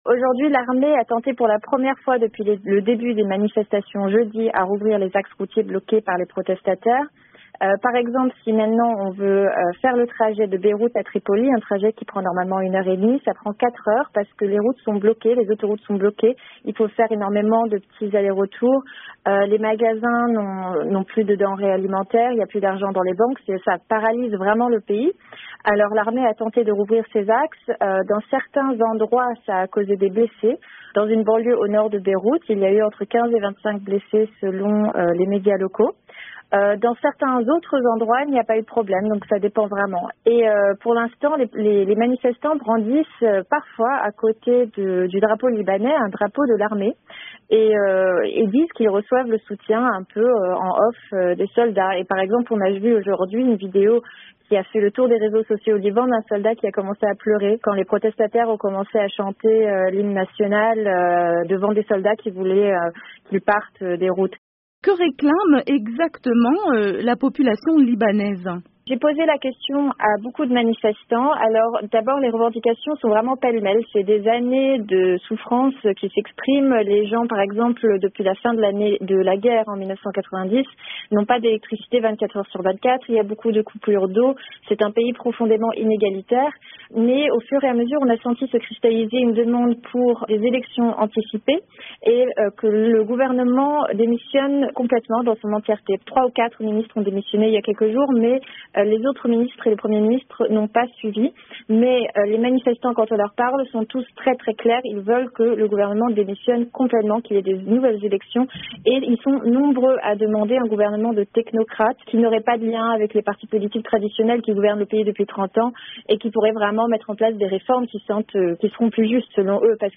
jointe à Beyrouth.